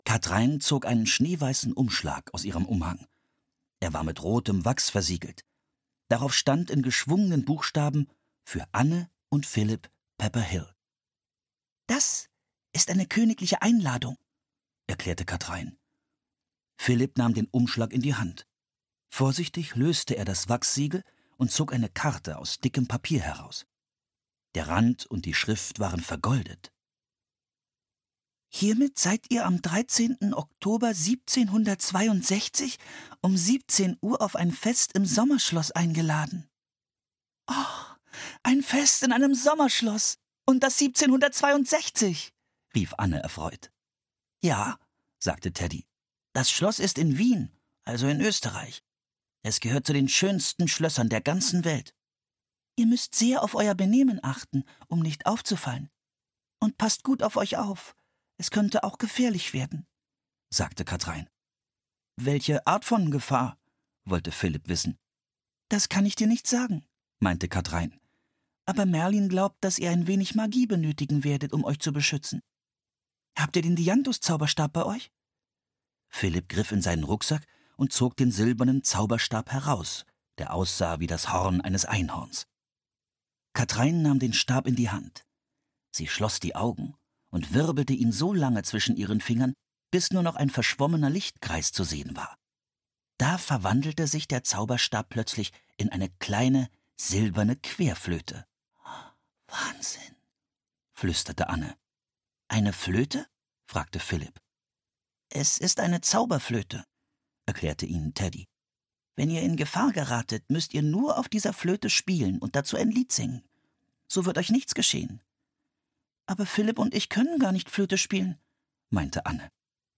Die geheime Macht der Zauberflöte (Das magische Baumhaus 39) - Mary Pope Osborne - Hörbuch